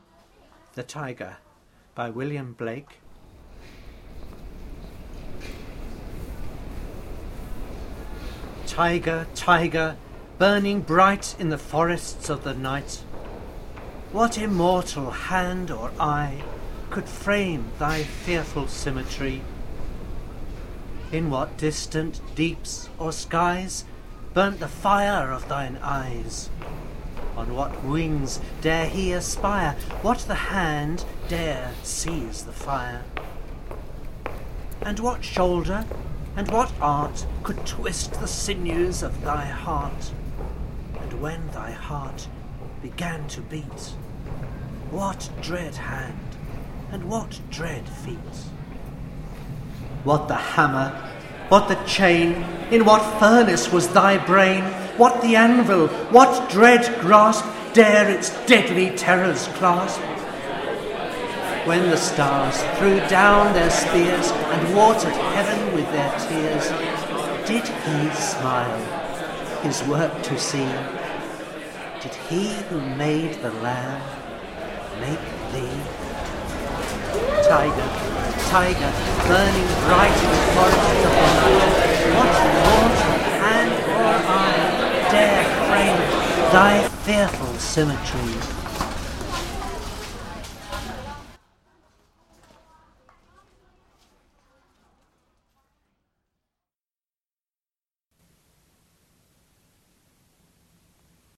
By doing this I wanted to highlight, and explore, how different spaces can create different sounds and atmospheres. By focussing on the spoken word in this I am trying to show how the impact and power of a poem is changed by how it is spoken, and how sound works in the place it is spoken.